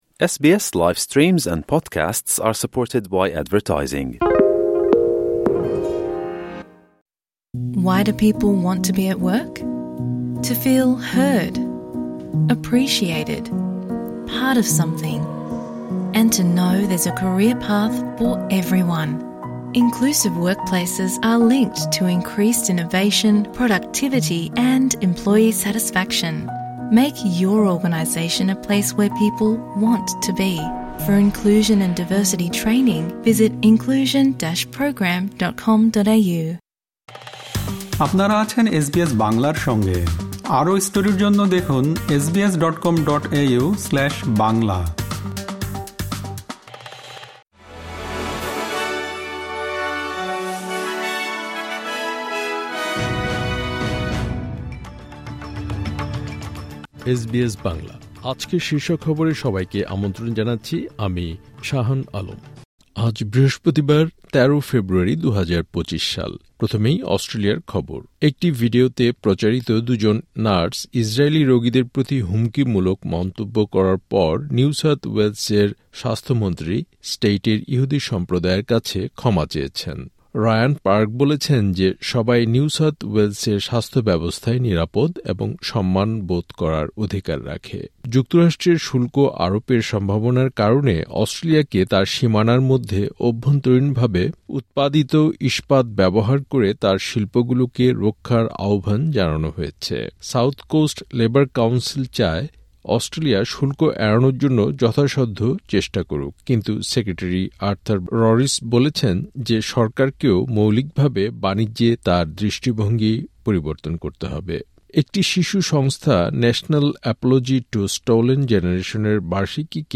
এসবিএস বাংলা শীর্ষ খবর: ১৩ ফেব্রুয়ারি, ২০২৫